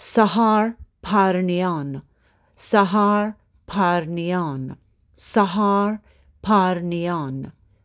(Avec prononciation audio)